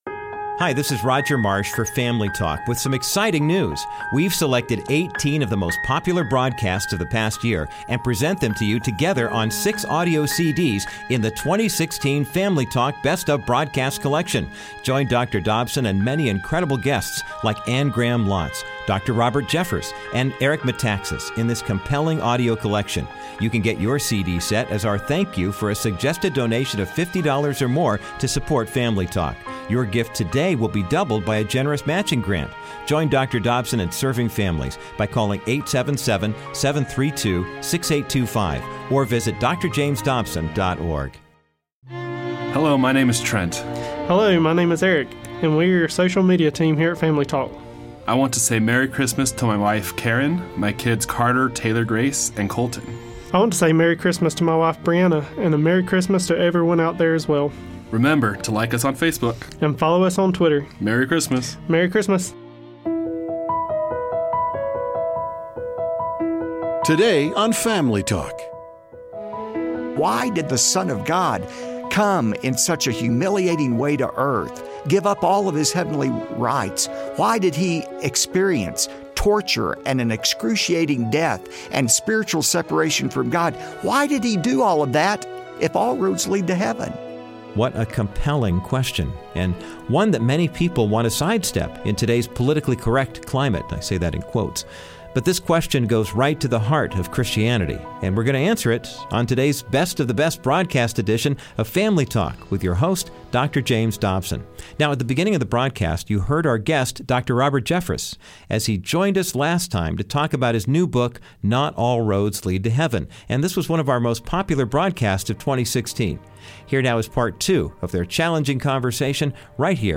Dr. Robert Jeffress answers some of the questions people struggle with when theyre faced with the exclusive claims of Jesus. Dont miss the conclusion of his challenging conversation on todays edition of Dr. James Dobsons Family Talk.